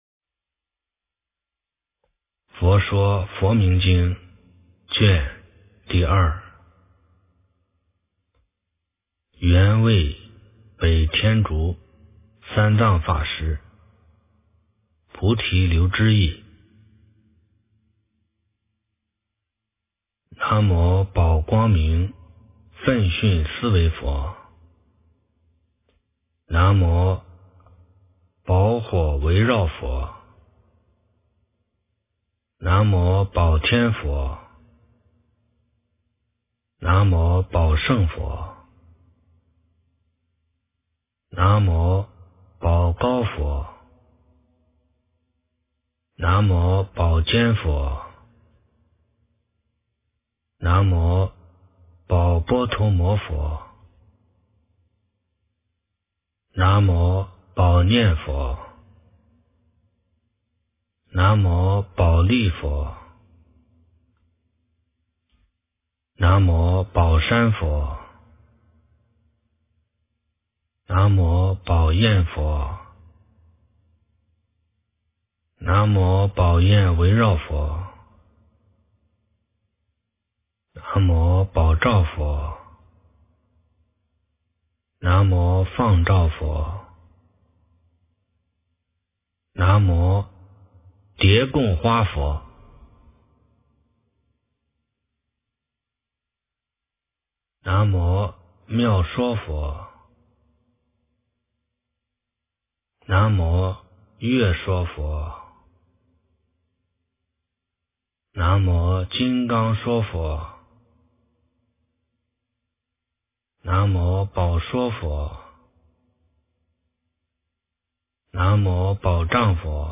万佛名经第02卷 - 诵经 - 云佛论坛